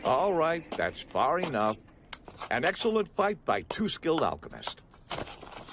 See If Y'all Can Chop This... Because I can't - Vox and Possible Percs.wav